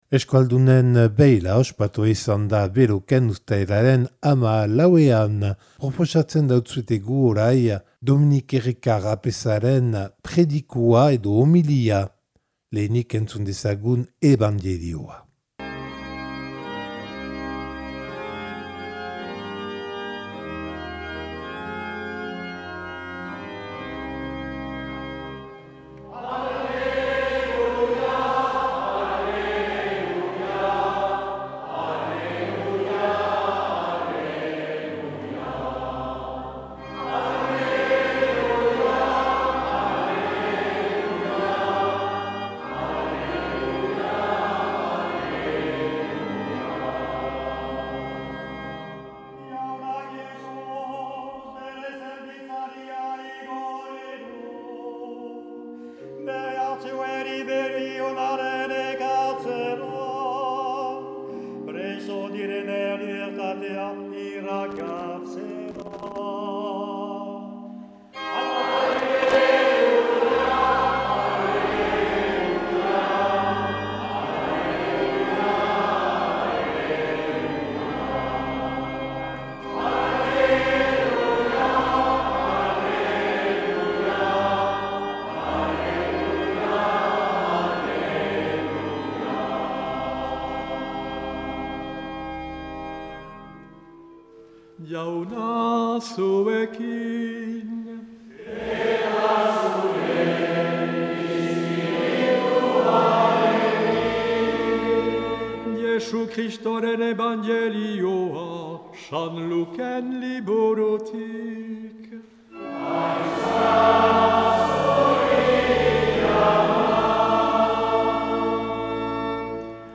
Euskaldunen beila Beloken 2025ko uztailaren 14an
homilia